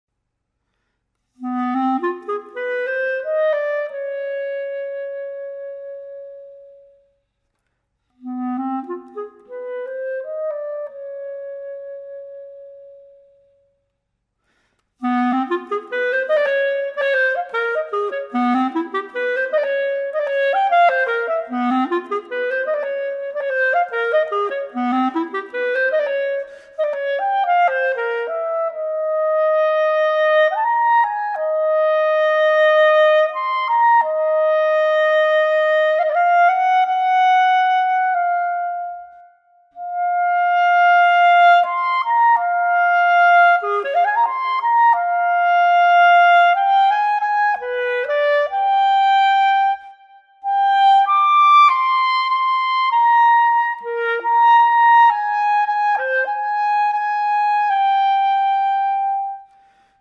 Flute solo